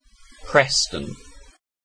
Ääntäminen
Ääntäminen UK UK : IPA : /ˈpɹɛstən/ Haettu sana löytyi näillä lähdekielillä: englanti Käännöksiä ei löytynyt valitulle kohdekielelle.